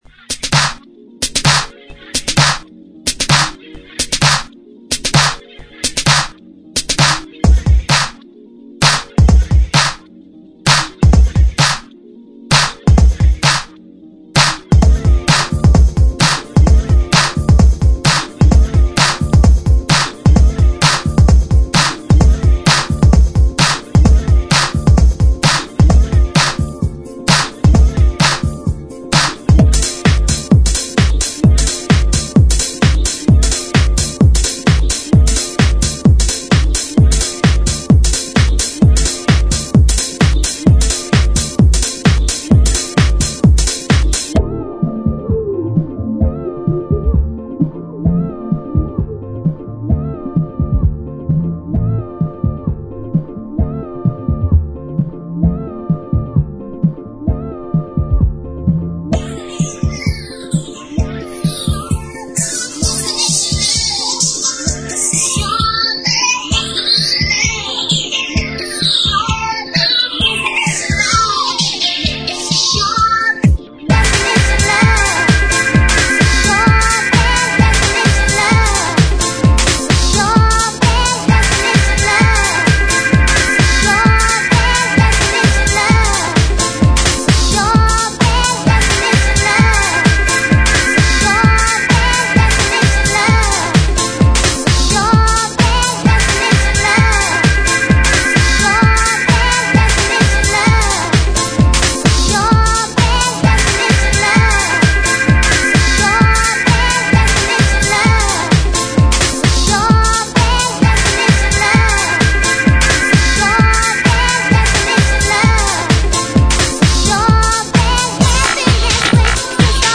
Vos Compos House
petite remarque perso... ça peut sembler un peut répétitif au bout d'un moment, j'aurai bien vu une variante rythmique après l'effet reverse, mais bon, "sensibilité perso",
sinon le thème est sympa